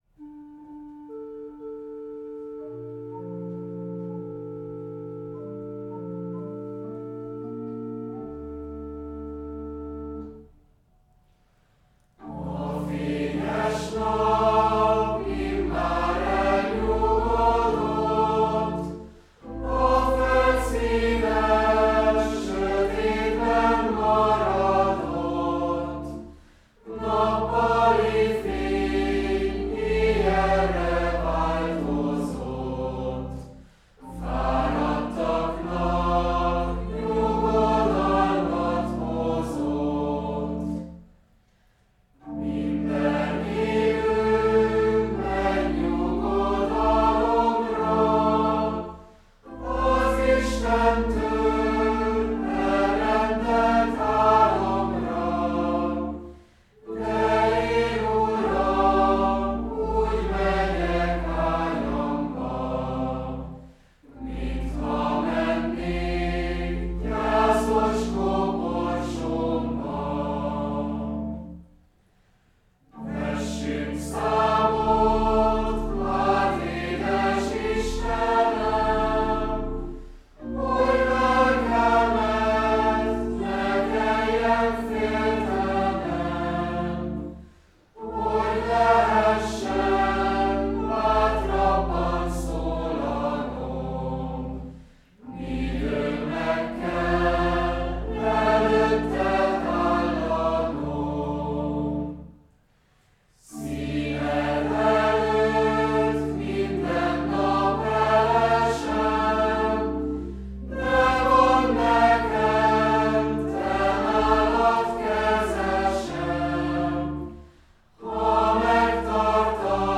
Csángó népi gyűjtés.
A ritmus alakítása (a sorok végi hosszú hangok rövidítése) elősegíti, hogy az ének felidézze a népdalok parlando, azaz szövegszerű előadásmódját, valamit megkönnyíti a felekezetközi használatot.